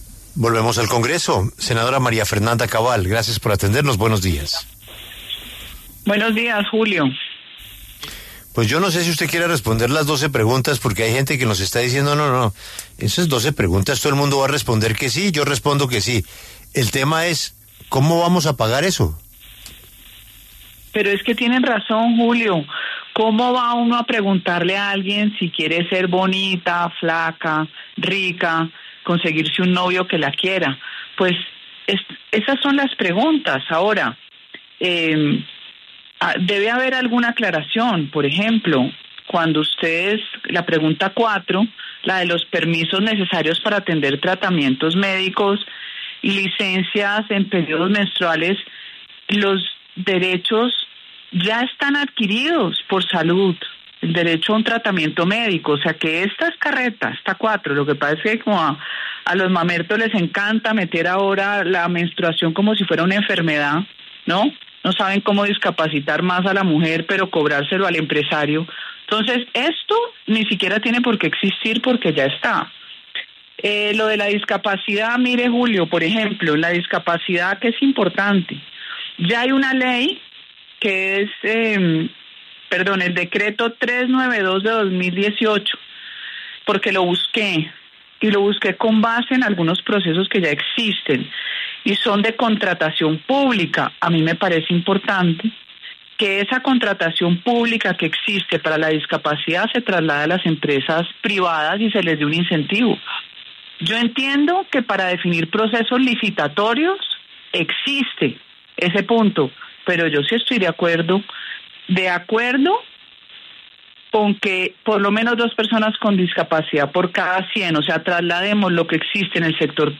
En entrevista en La W, la senadora María Fernanda Cabal, del Centro Democrático, lanzó duras críticas contra la propuesta de consulta popular del presidente Gustavo Petro, luego de que se conocieran este 22 abril las 12 preguntas planteadas para abordar la reforma laboral que se hundió en el Senado.